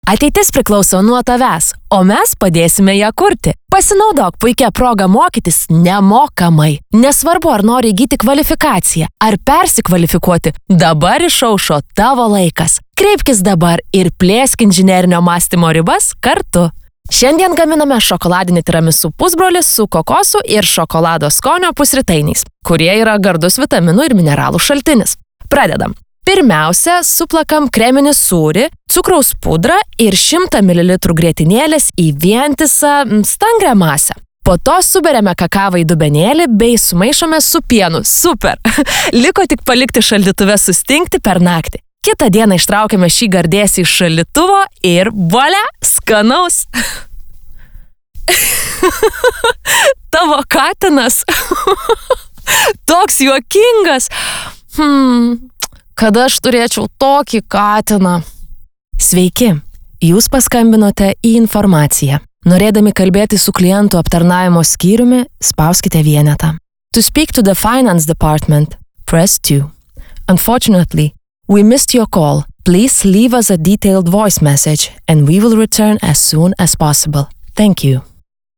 Diktoriai